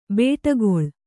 ♪ bēṭagoḷ